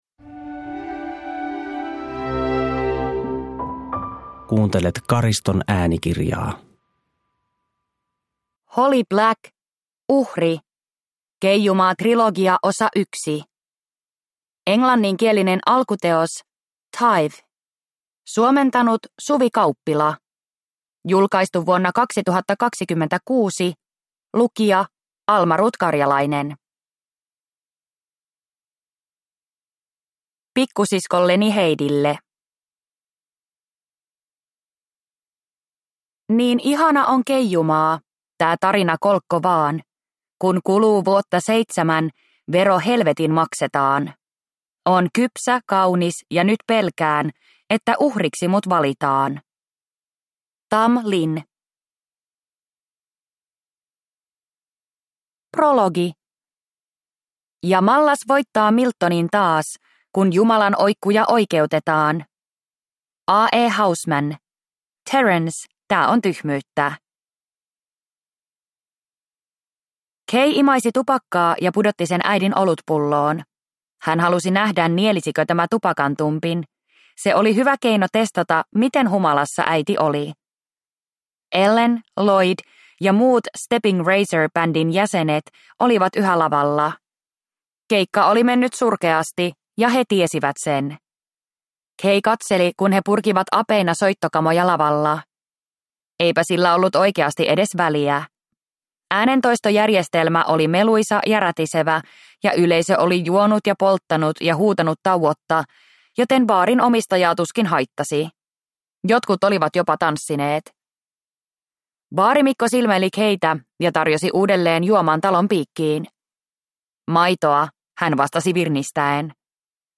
Uhri – Ljudbok